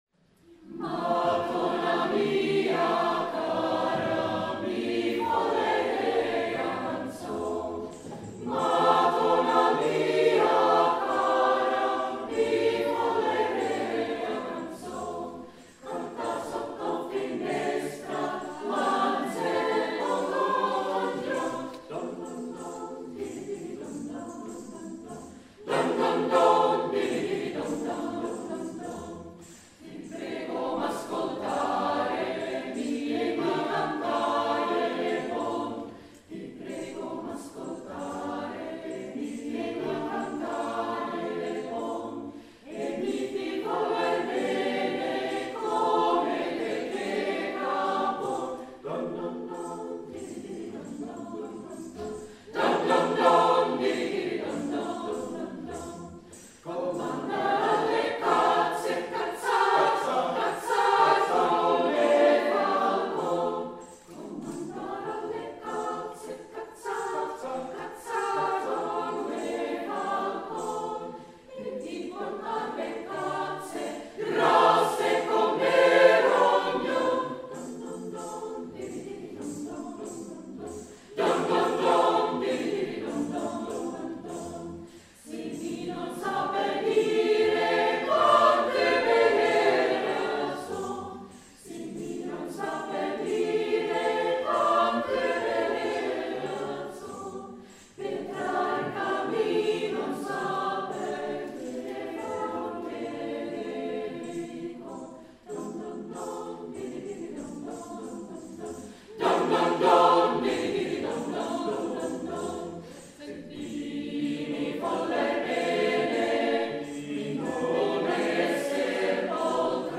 Sångerna är dels exempel på dansstilar och dels renässanspärlor.